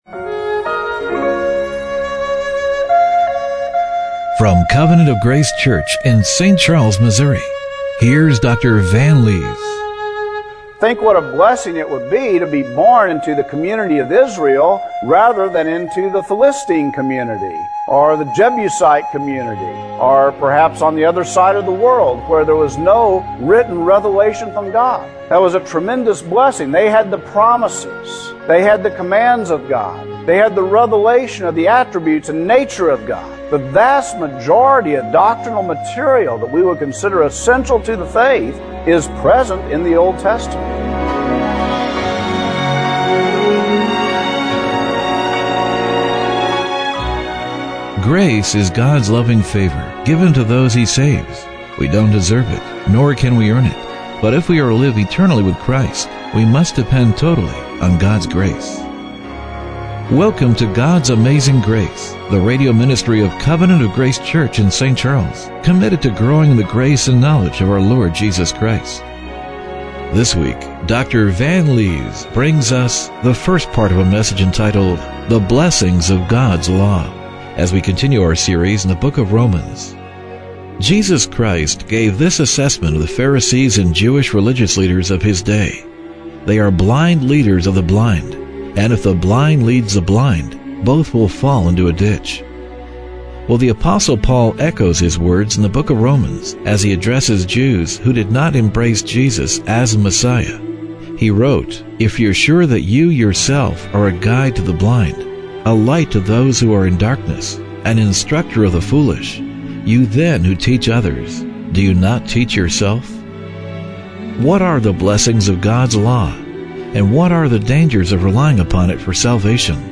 Romans 2:17-29 Service Type: Radio Broadcast What are the blessings of God's law and what are the dangers of relying upon it for salvation?